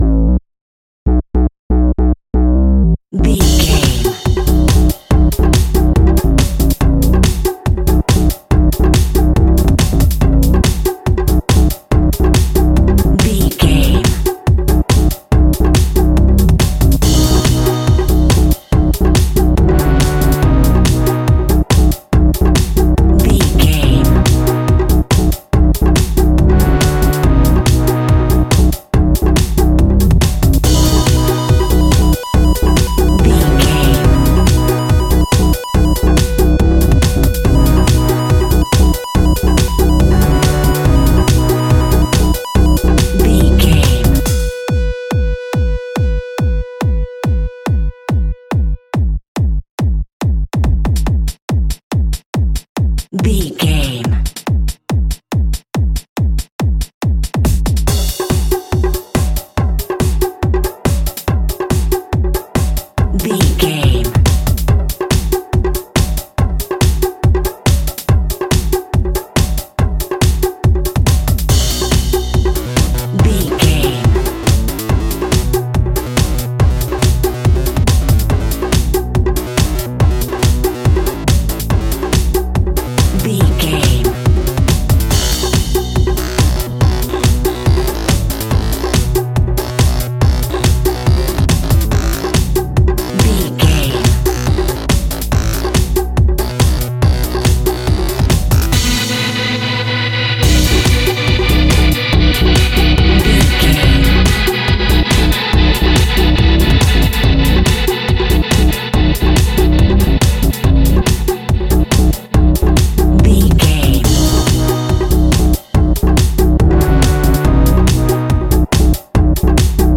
Epic / Action
Fast paced
Aeolian/Minor
aggressive
powerful
dark
driving
energetic
drum machine
synthesiser
electro house
synth bass
synth leads
percussion